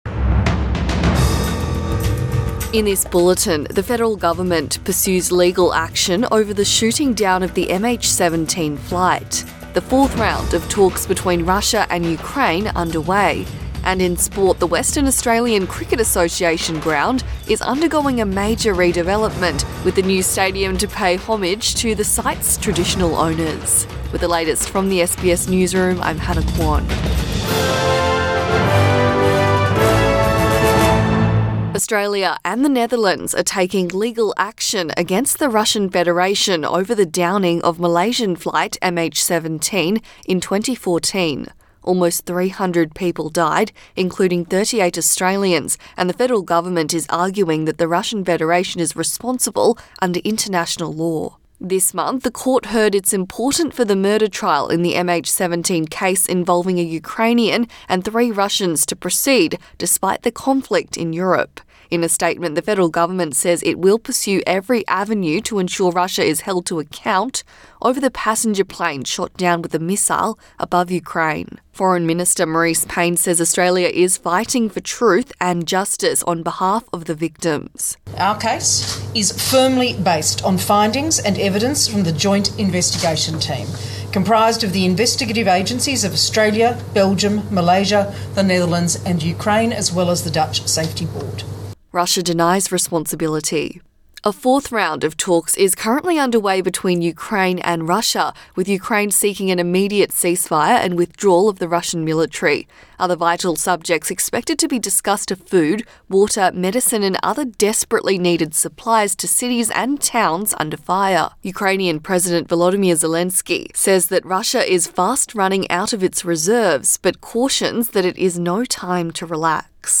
AM bulletin 15 March 2022